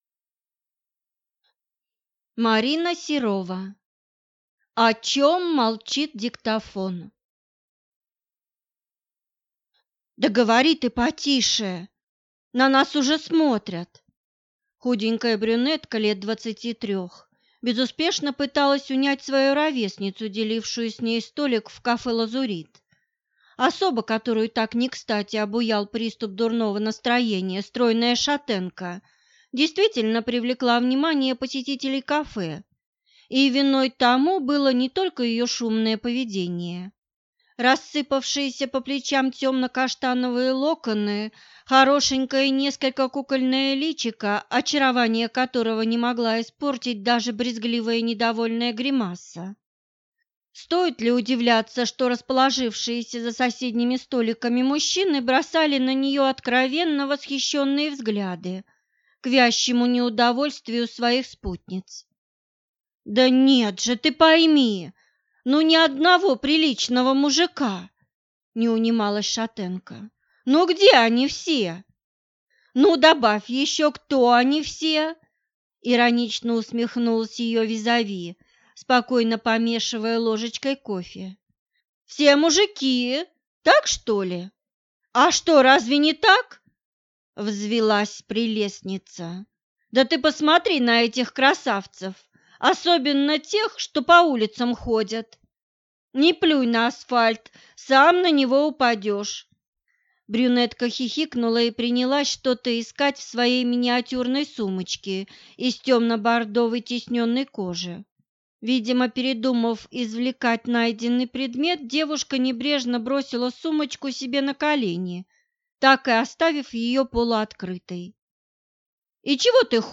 Аудиокнига О чем молчит диктофон | Библиотека аудиокниг